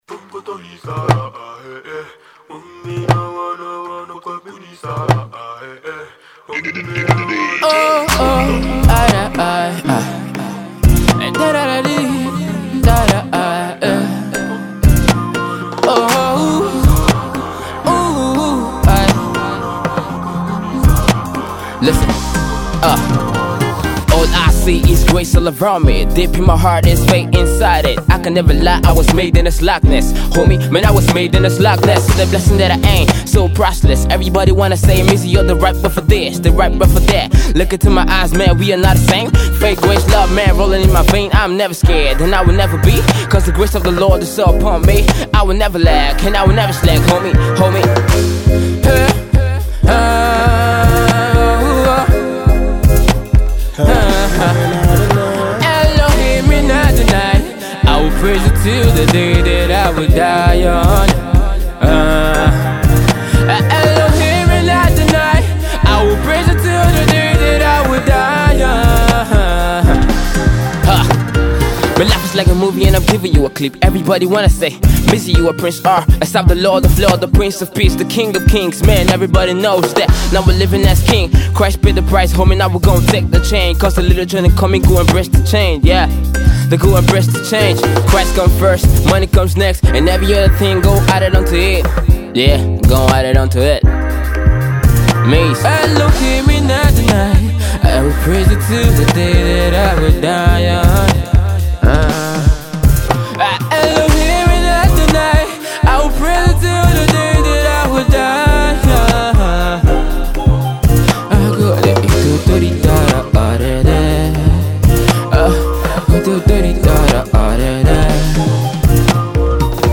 christian hip hop and Afro pop